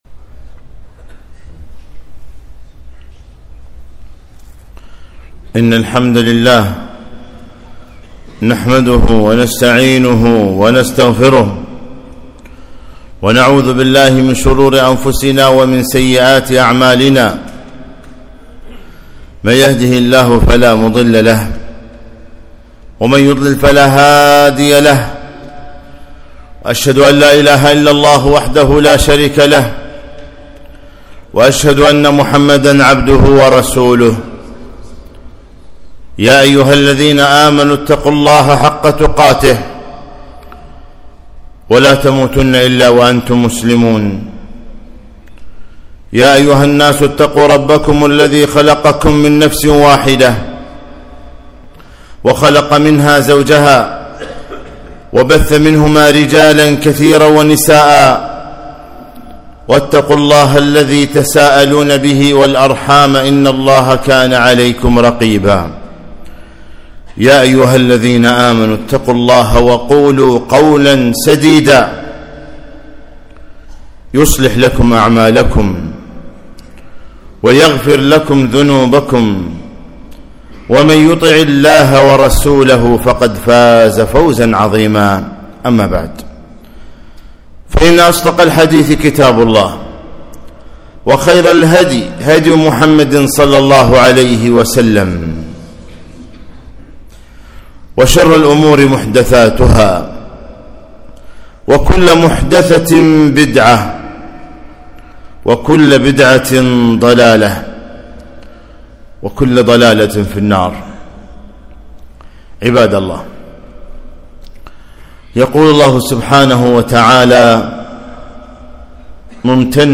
خطبة - صيانة اللسان